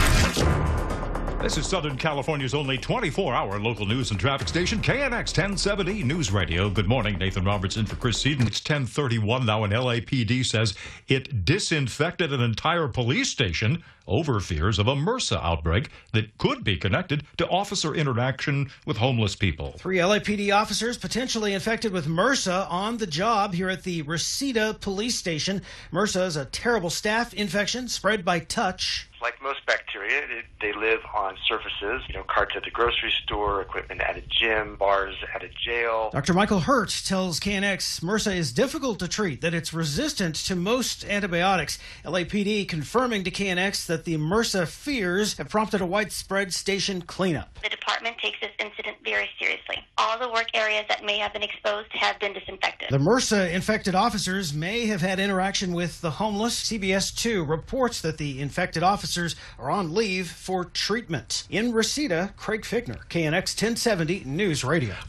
KNX1070 Radio - MRSA infections affecting LAPD officers